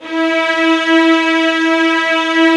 VIOLINS F#-R.wav